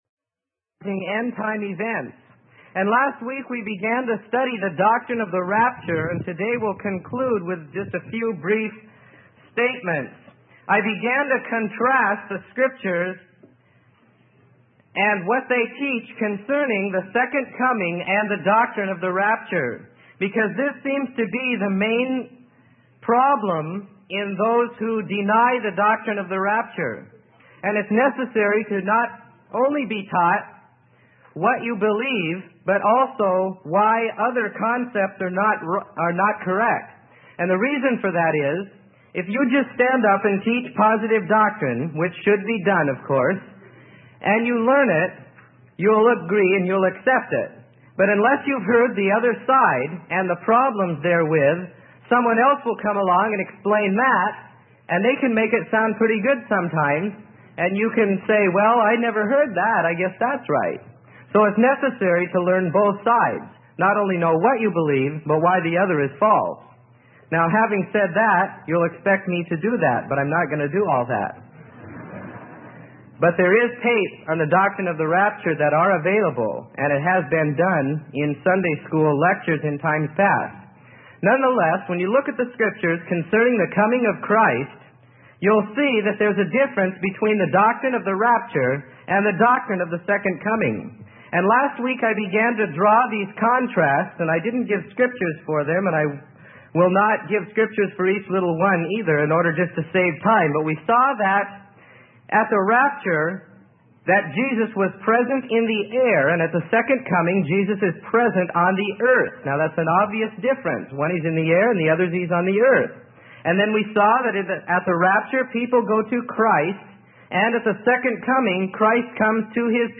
Sermon: End Time Events - Part 2 - Freely Given Online Library